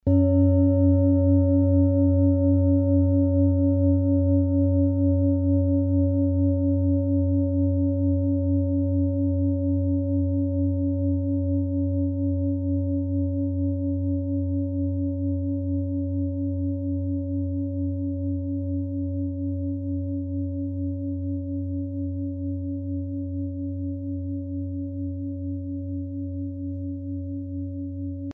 Klangschalen-Typ: Tibet
Klangschale Nr.1
Gewicht = 2400g
Durchmesser = 29,5cm
(Aufgenommen mit dem Filzklöppel/Gummischlegel)
klangschale-set-6-1.mp3